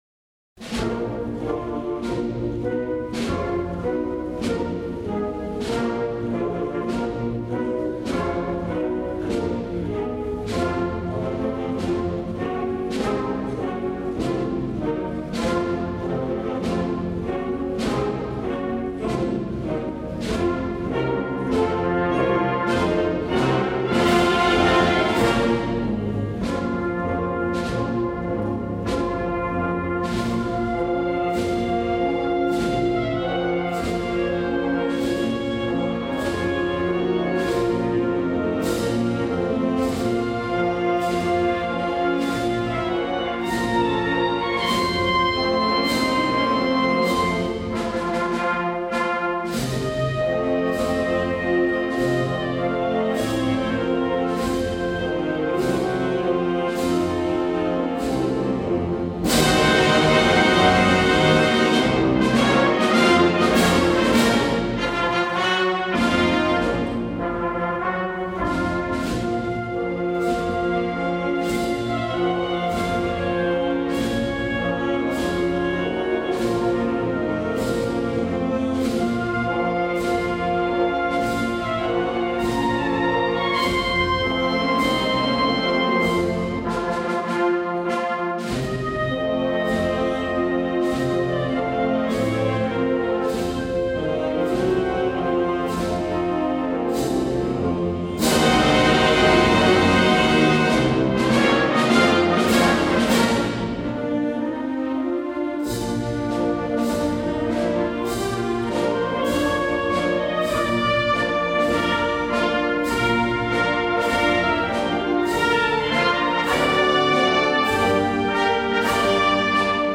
Marchas procesionales
Para Bandas de Música